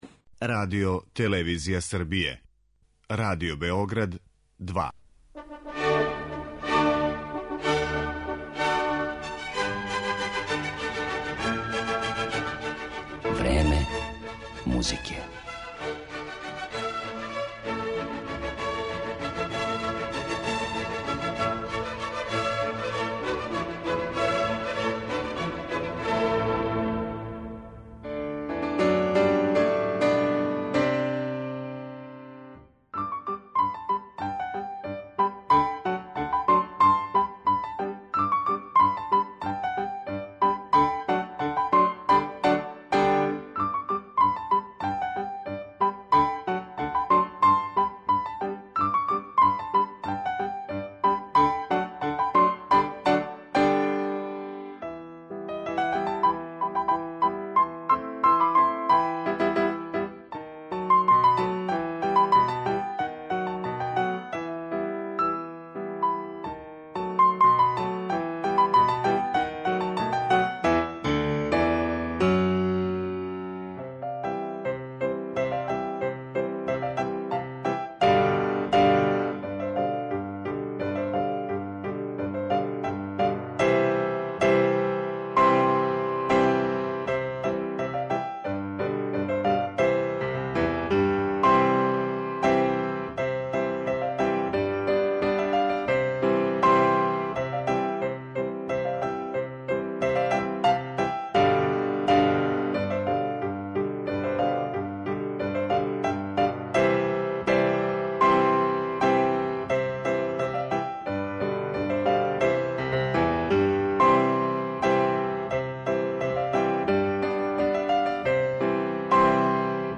моћи ћете да слушате репортажу са ове промоције.